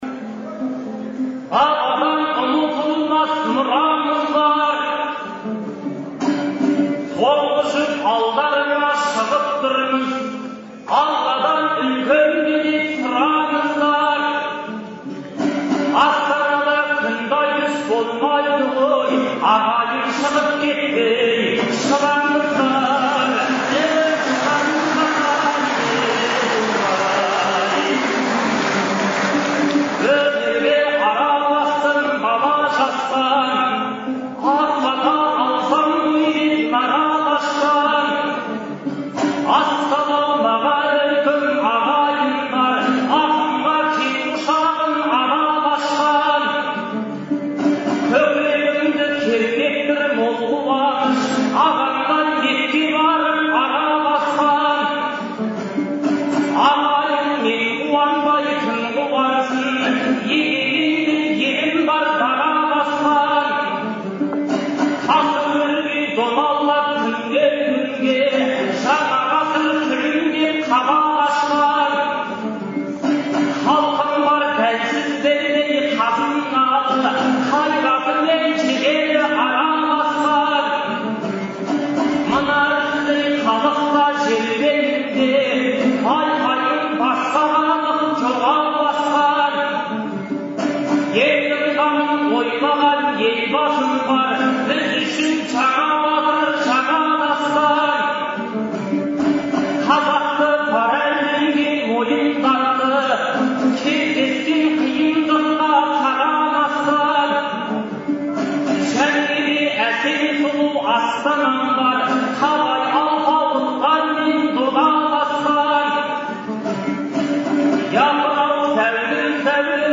Шілденің 8-9-ы күндері Астанадағы «Қазақстан» орталық концерт залында «Ел, Елбасы, Астана» деген атпен ақындар айтысы өтті. Айтыстың алғашқы күні 10 жұп (20 ақын) сөз сайыстырды.